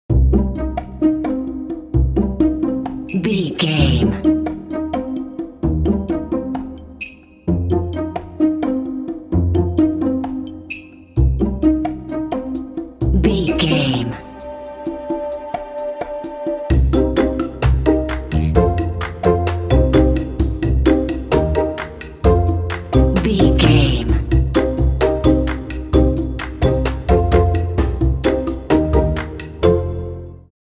Aeolian/Minor
playful
foreboding
cinematic